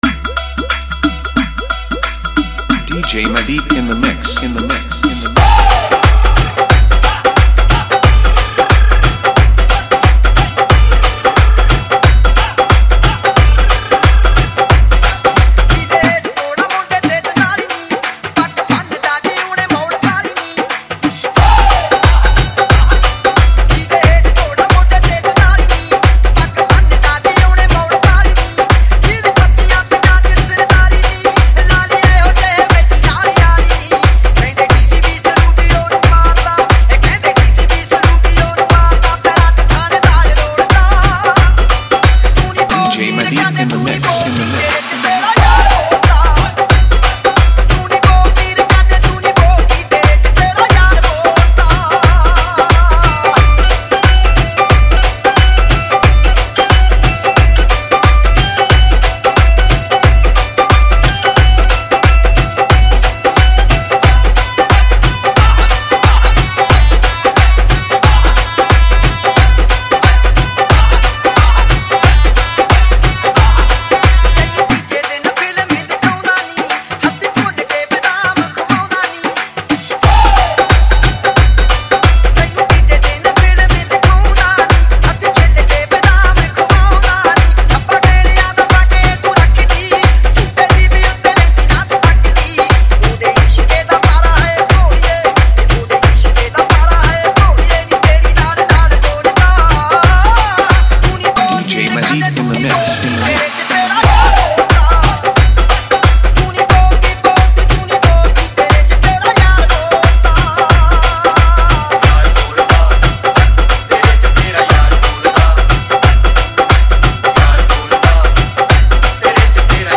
Punjabi Remix